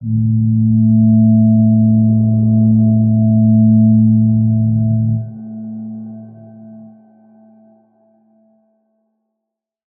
G_Crystal-A3-mf.wav